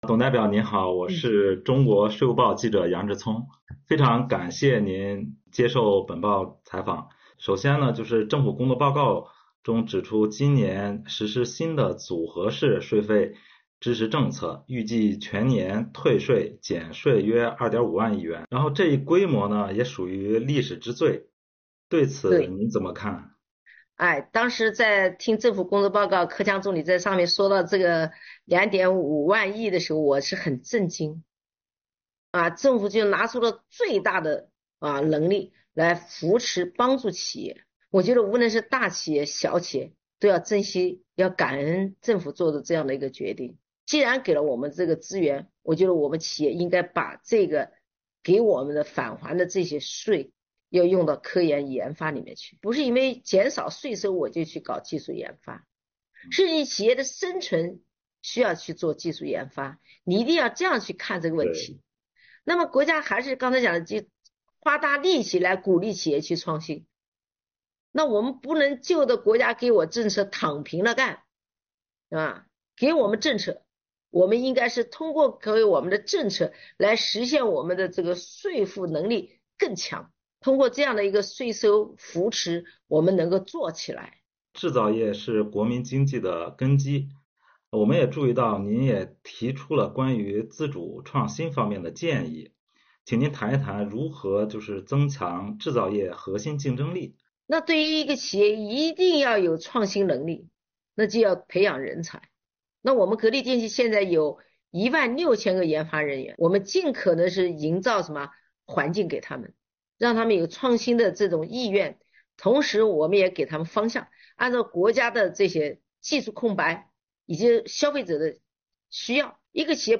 全国人大代表、格力电器董事长兼总裁董明珠在接受本报记者专访时说，国家花如此大力气助企纾困，企业要珍惜、用好国家的税费优惠政策。